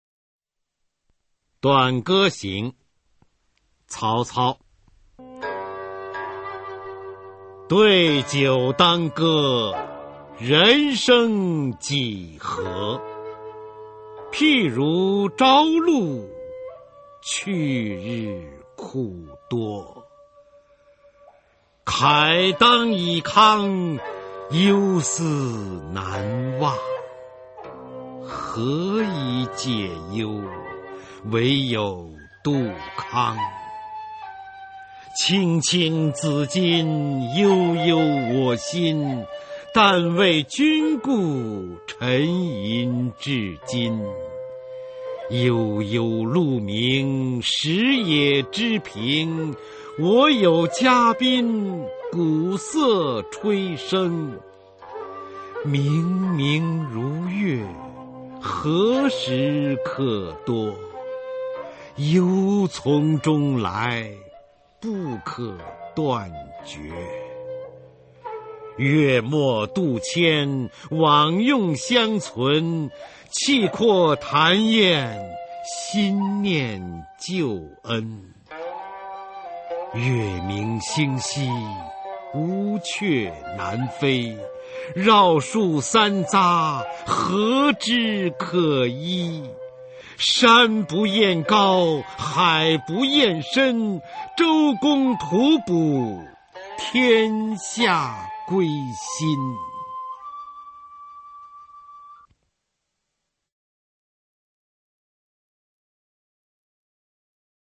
[魏晋诗词诵读]曹操-短歌行（男） 古诗朗诵